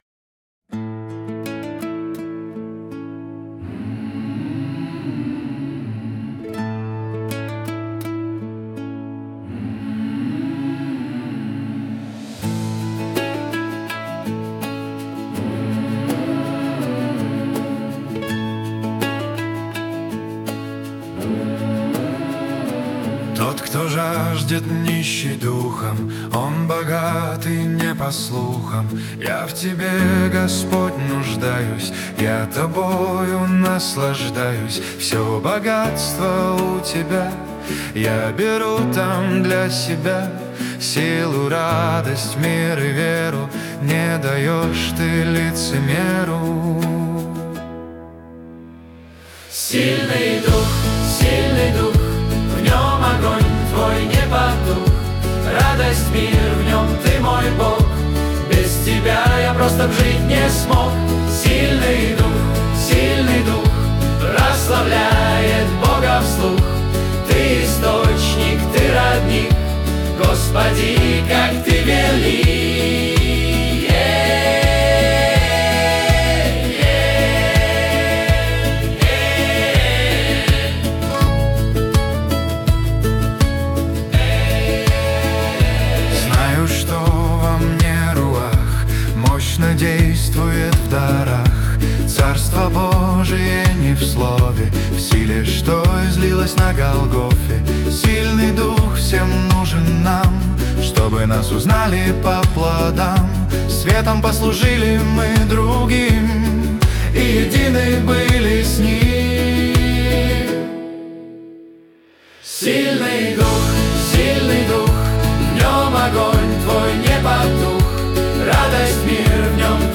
песня ai
444 просмотра 1076 прослушиваний 132 скачивания BPM: 82